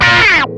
MANIC SLIDE2.wav